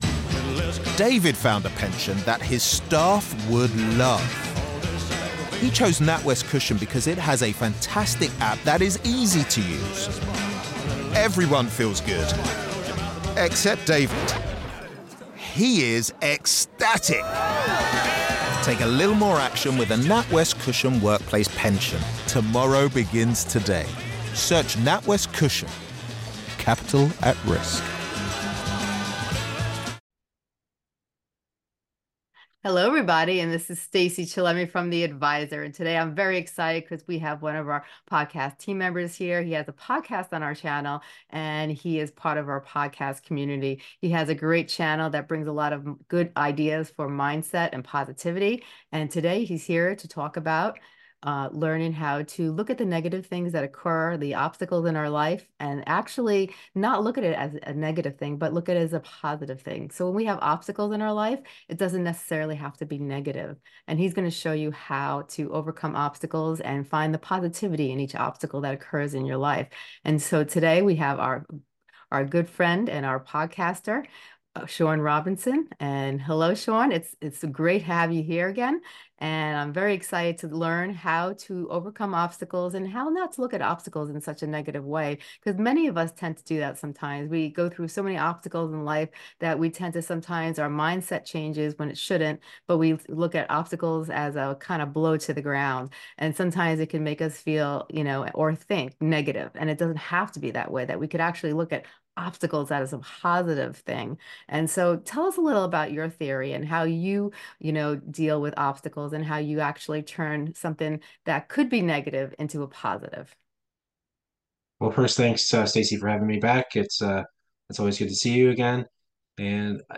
In this podcast episode, unlock the transformative impact of leveraging obstacles and harnessing their potential for personal growth. Gain valuable strategies, compelling anecdotes, and practical tips to equip you to navigate adversities with wisdom and resilience. This engaging conversation will leave you empowered and enlightened, ready to embrace life's obstacles as catalysts for growth and success.